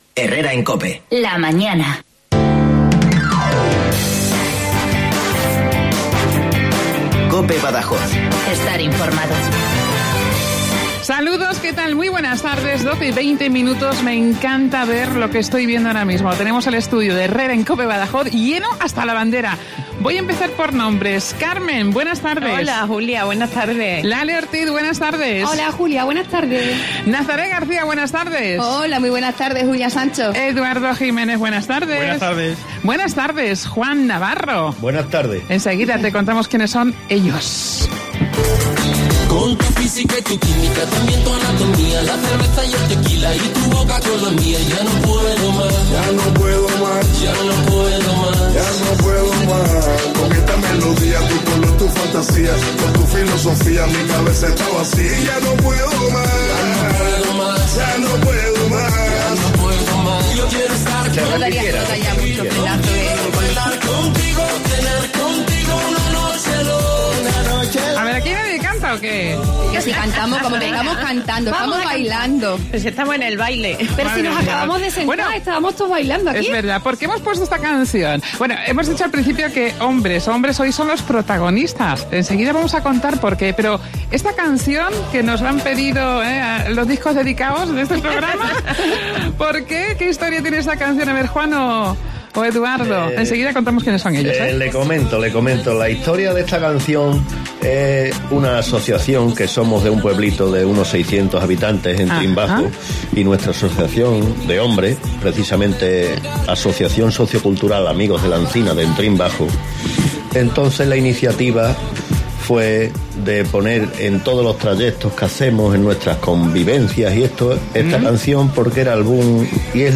Hoy nos han visitado 2 de los 74 miembros de la única asociación masculina, que nos dicen, hay en Extremadura. La Asociación Sociocultural "La Encina" de la localidad de Entrín.